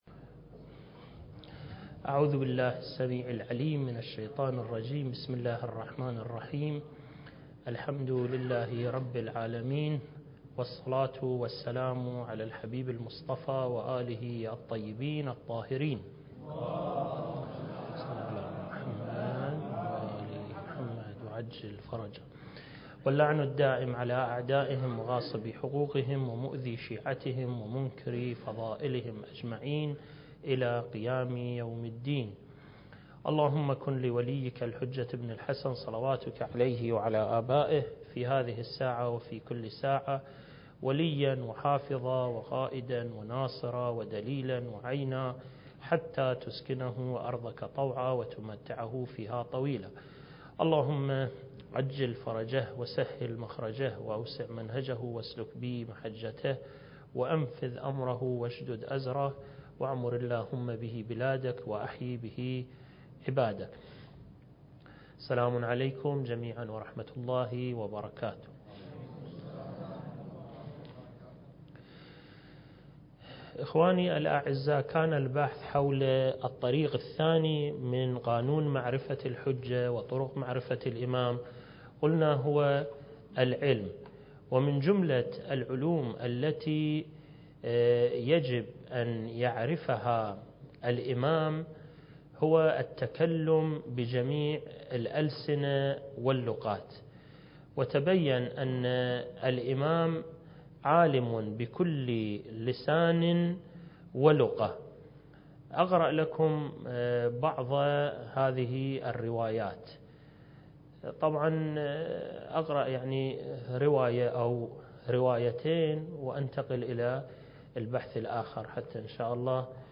المكان: مؤسسة الإمام الحسن المجتبى (عليه السلام) - النجف الأشرف دورة منهجية في القضايا المهدوية (رد على أدعياء المهدوية) (8) التاريخ: 1443 للهجرة